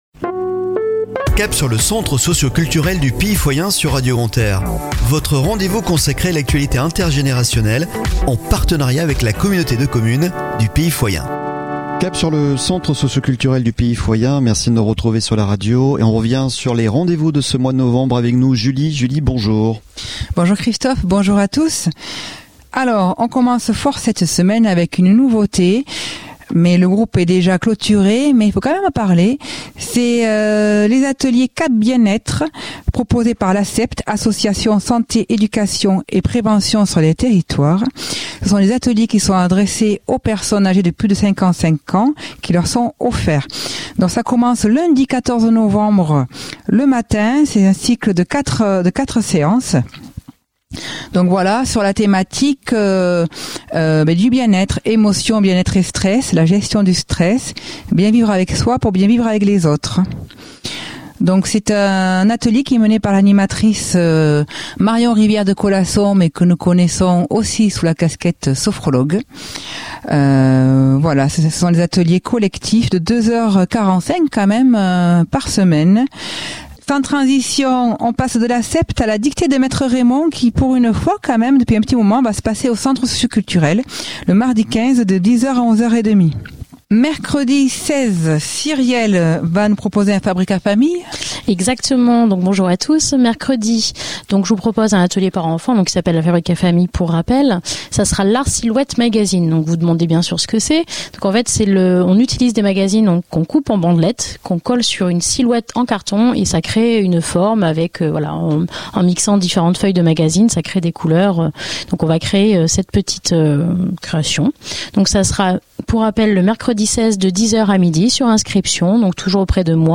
Chronique de la semaine du 14 au 20 Novembre 2022 !